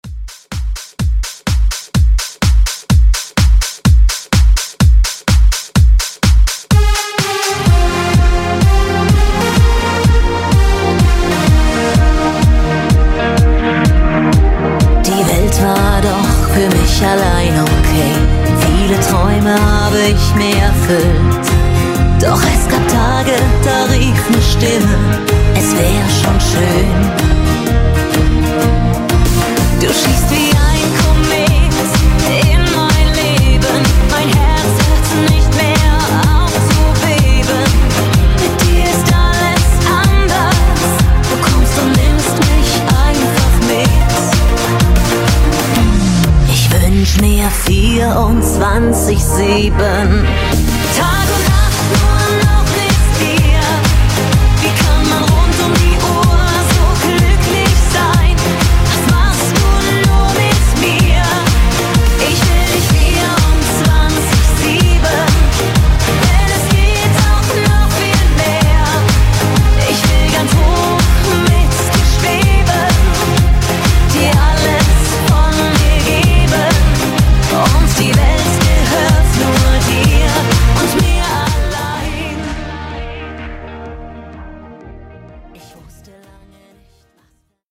Genres: DANCE , RE-DRUM , TOP40
Clean BPM: 128 Time